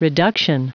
Prononciation du mot reduction en anglais (fichier audio)
Prononciation du mot : reduction